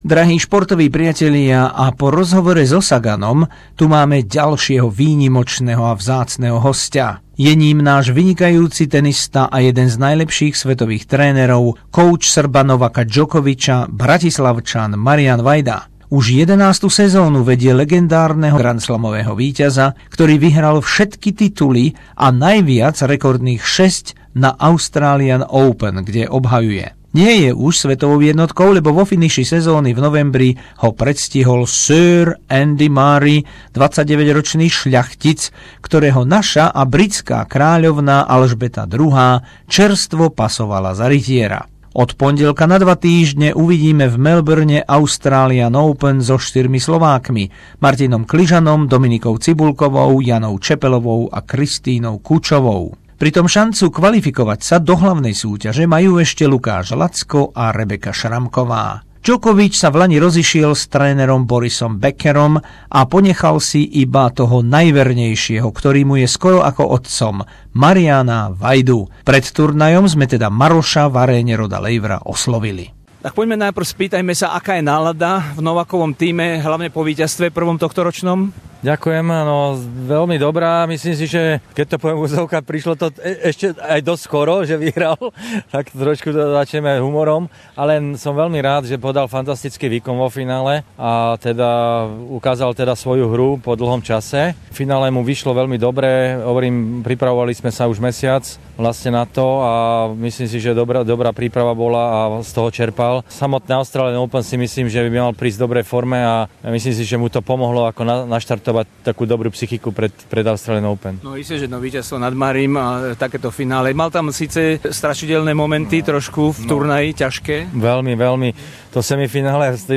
Rozhovor s Mariánom Vajdom, trénerom Novaka Djokoviča